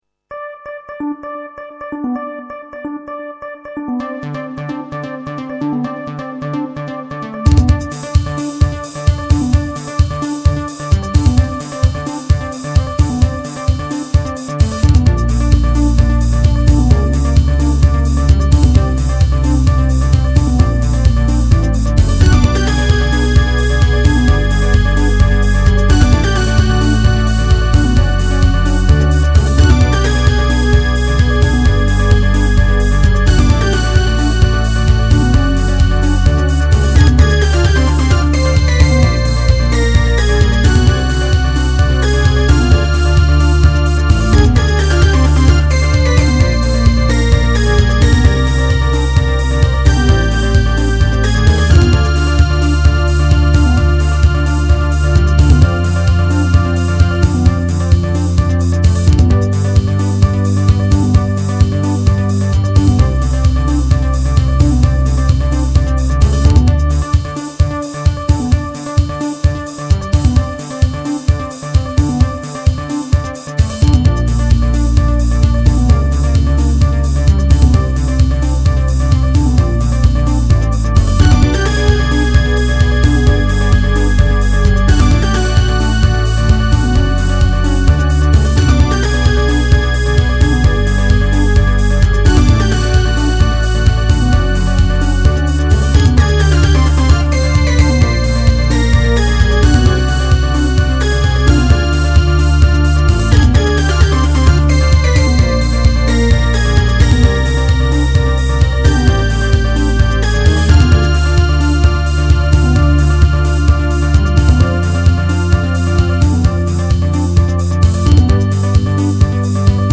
・シューティングっぽいもの第1弾